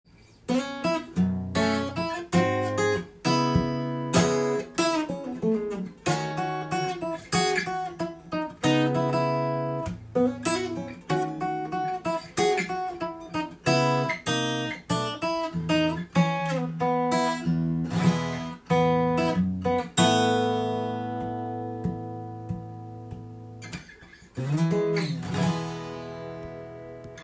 一人で弾くという形式での演奏になります。
必ずコードとメロディーを一緒に弾くようにします。
休符でメロディーがない時はコードのみでＯＫです。